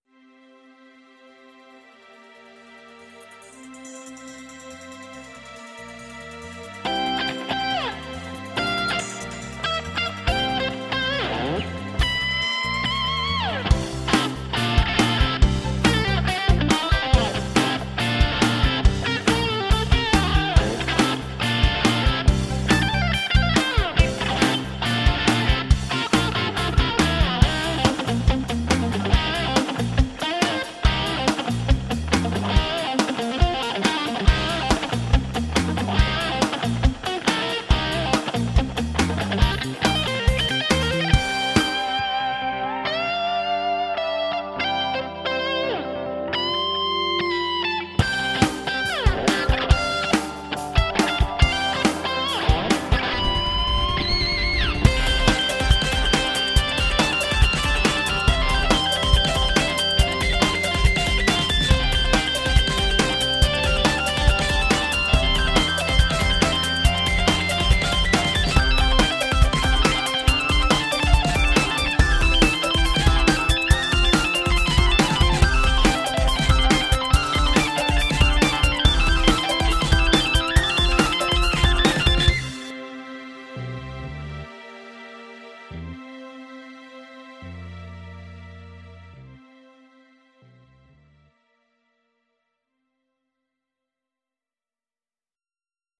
[Pop]